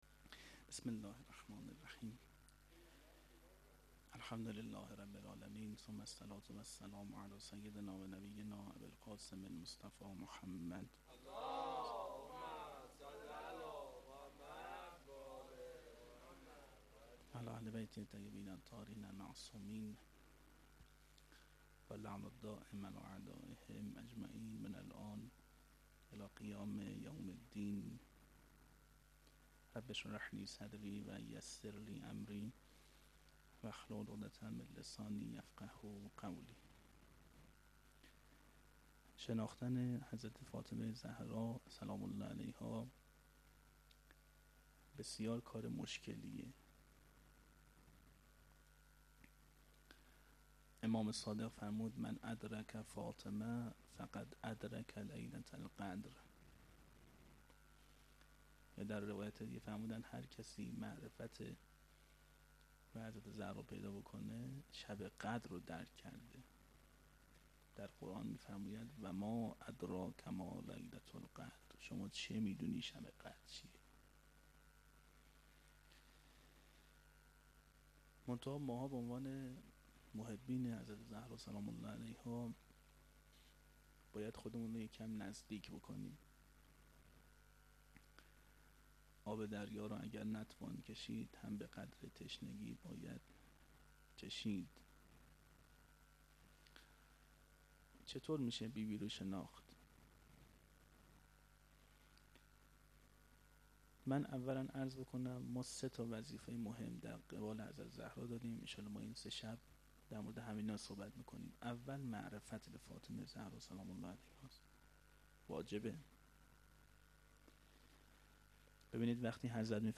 sokhanrani-fatemie92-shab1.mp3